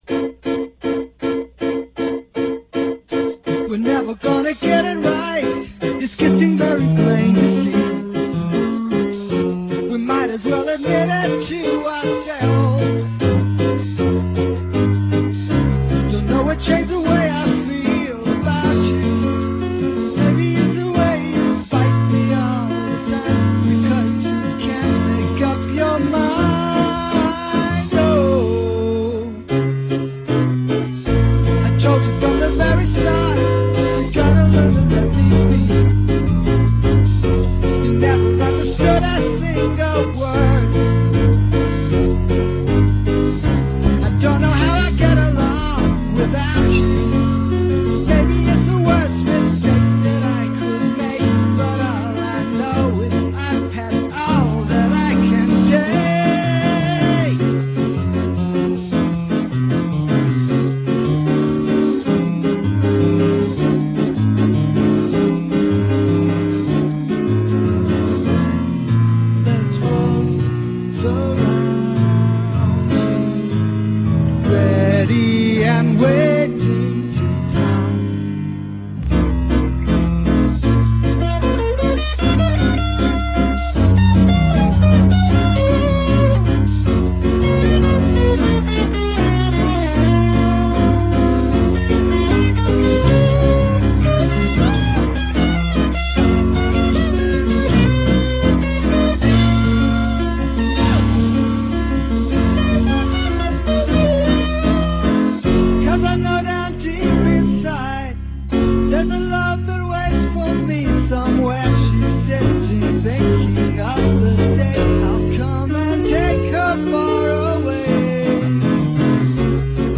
My favorite part, though, is definitely the guitar solo.